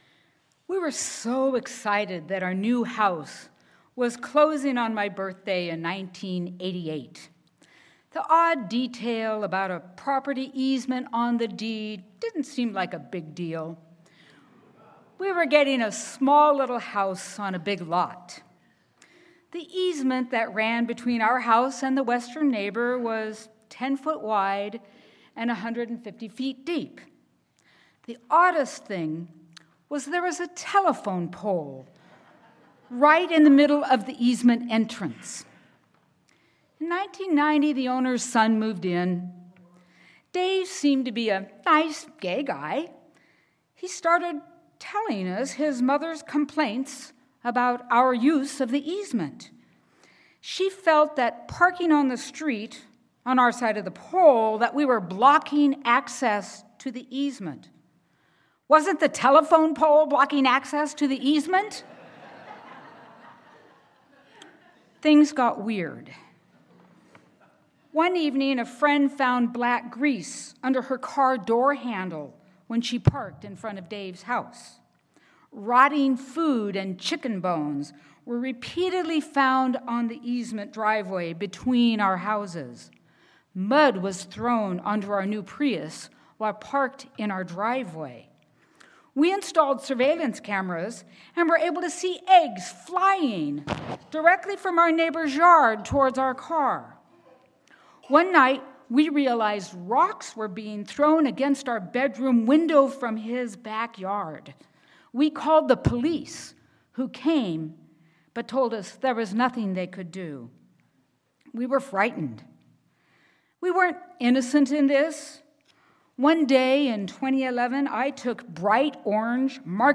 Sermon-Compassion-and-War.mp3